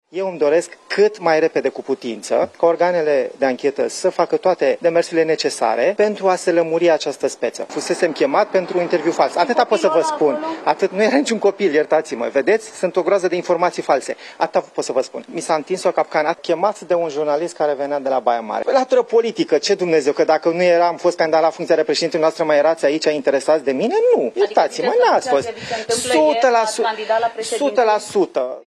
Pe treptele unei secții de poliție din București